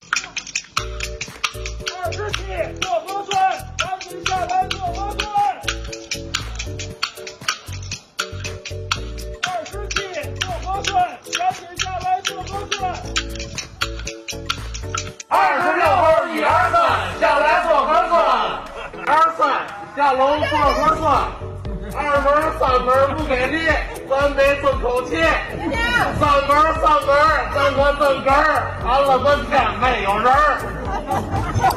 快板一响，韵脚押起来